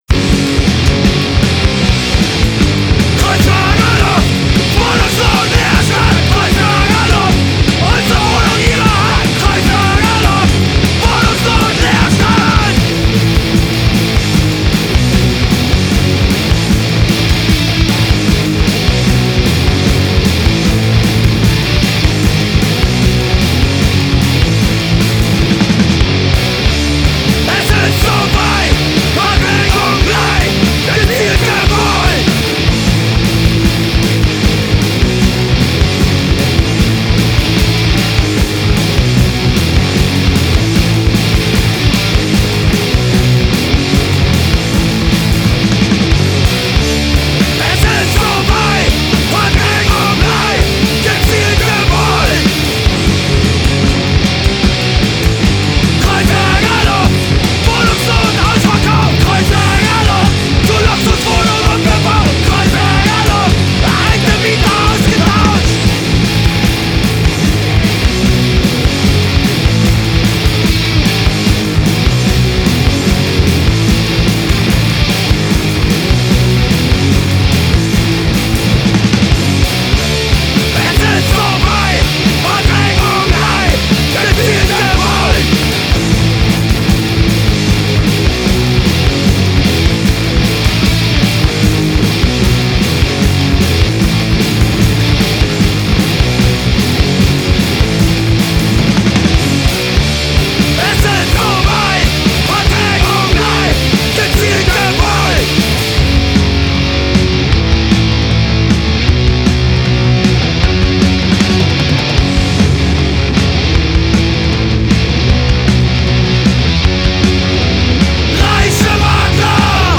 Musik zwischen Wut und Wahnsinn